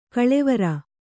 ♪ kaḷevara